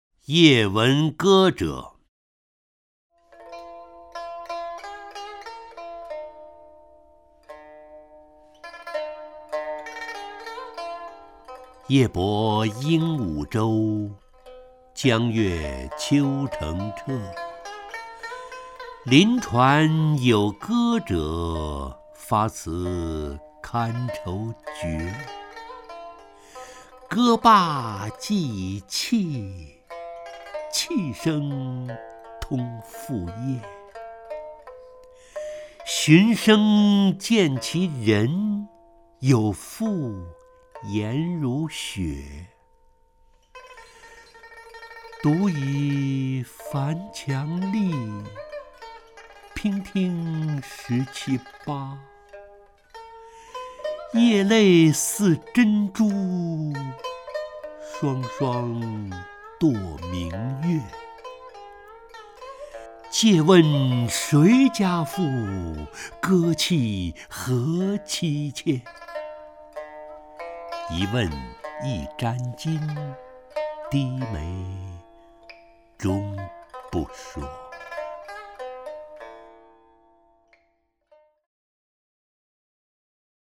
陈醇朗诵：《夜闻歌者》(（唐）白居易)
名家朗诵欣赏 陈醇 目录